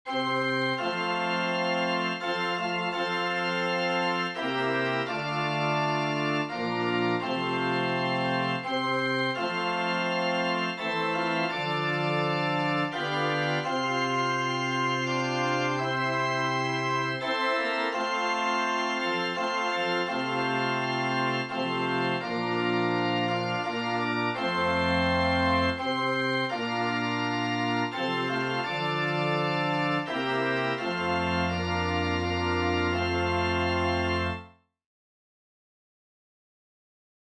Organ/Organ Accompaniment, SATB, SATB quartet
Voicing/Instrumentation: SATB , Organ/Organ Accompaniment , SATB quartet We also have other 38 arrangements of " Amazing Grace ".